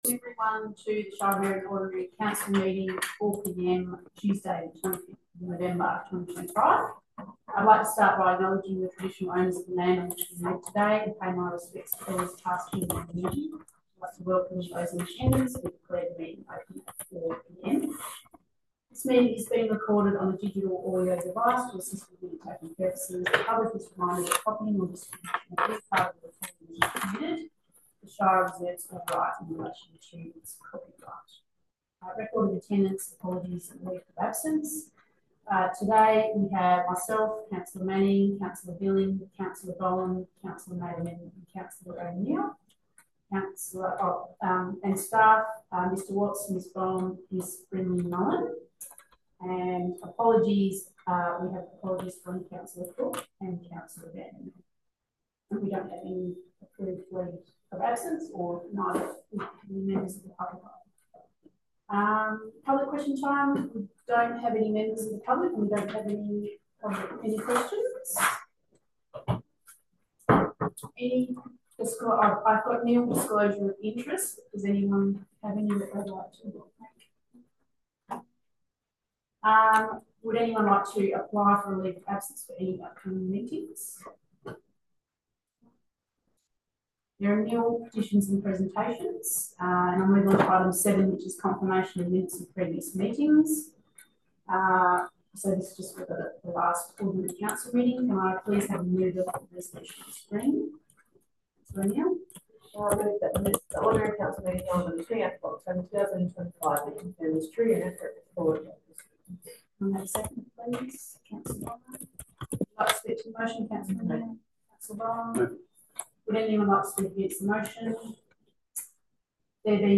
Location: Council Chambers